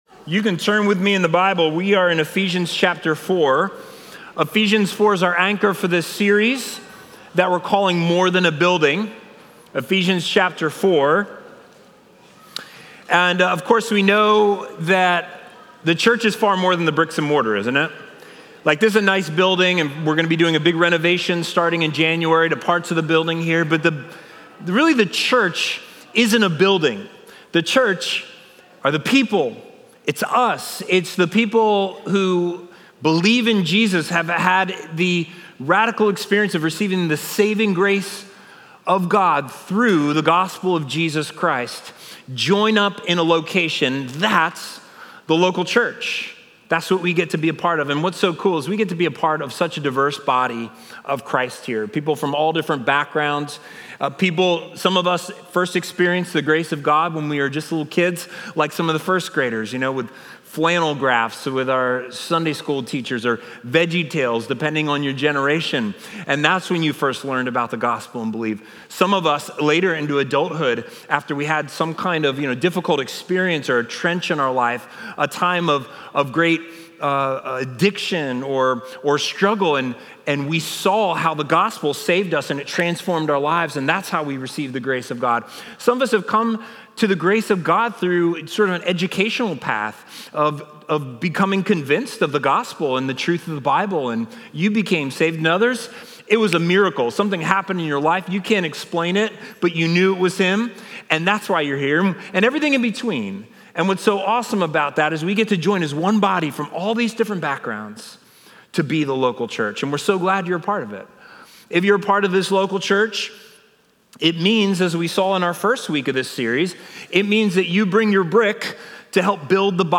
Watch previously recorded Sunday sermons.